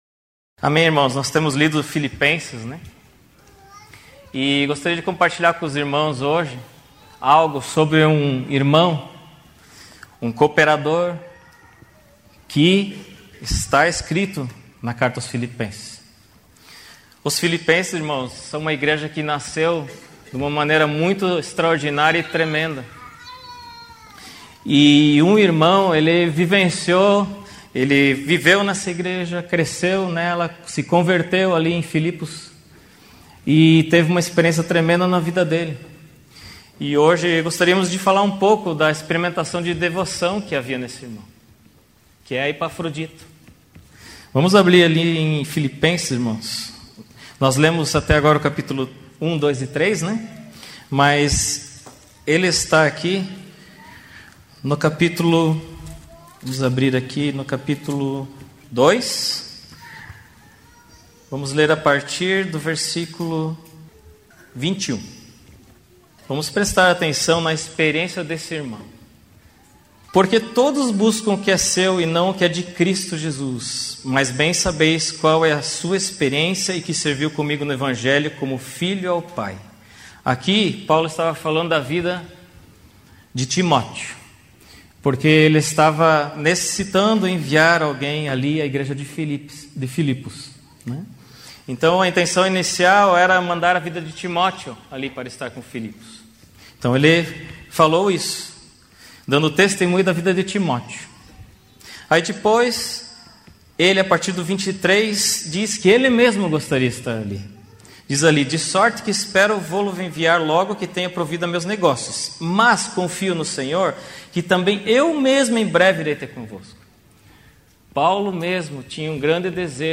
Mensagem compartilhada
na reunião da igreja em Curitiba